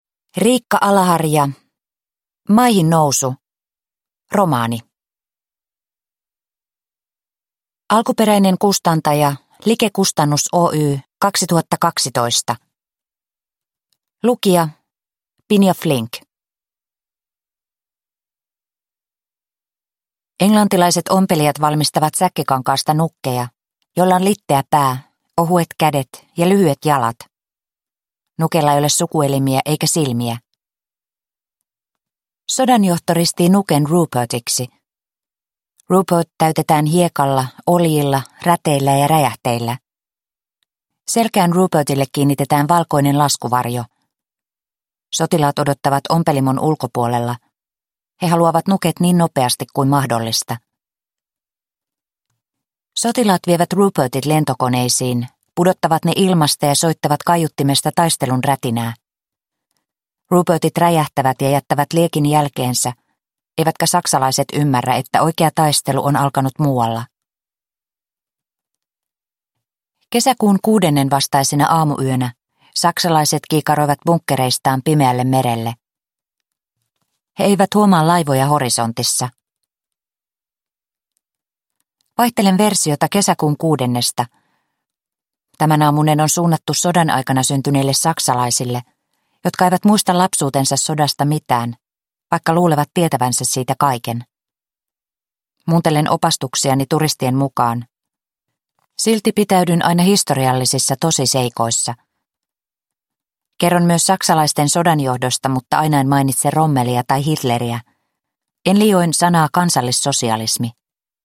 Maihinnousu – Ljudbok – Laddas ner